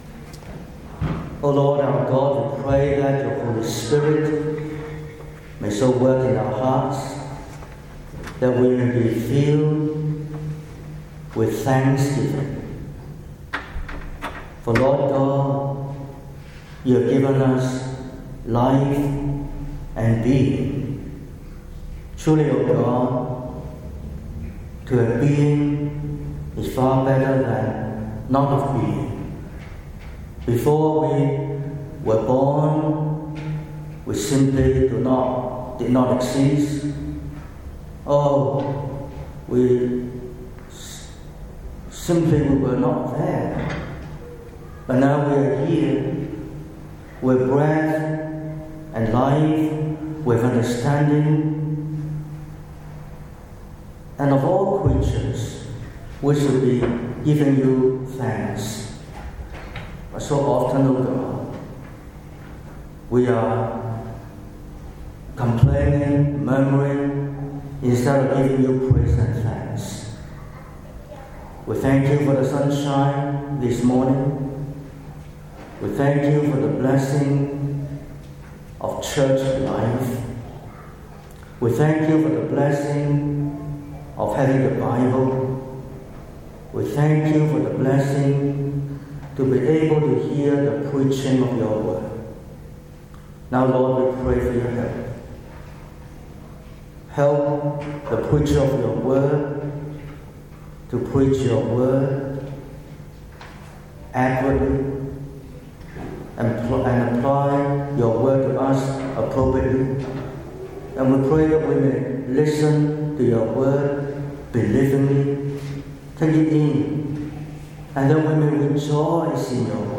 28/09/2025 – Morning Service: The latter days and the day of the Lord – Isaiah ch. 2